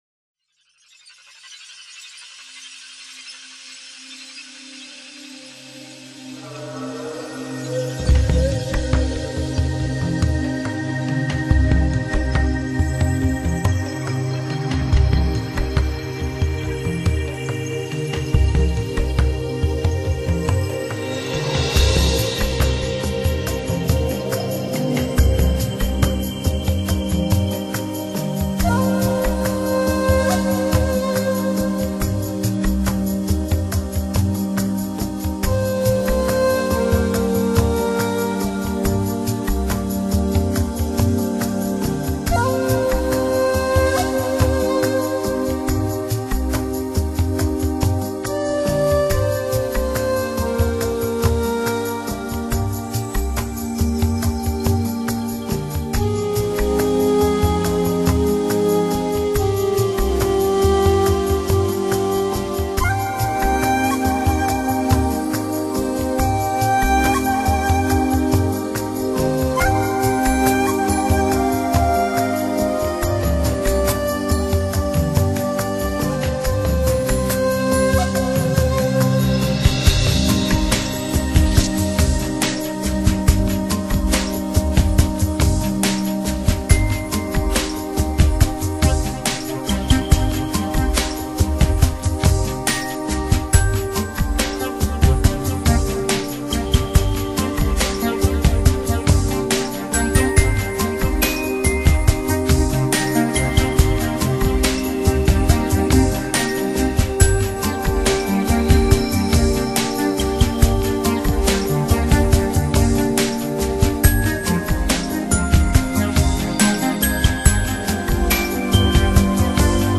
音乐类型: Newage
尺八悠扬的旋律响起，落日的余辉，撒在森林的角落，把满地的落叶染得通红。
金属片的振响沉淀在尺八低沉而圆润的节奏中，电子音乐的旋律，哄托起尺入的低郁。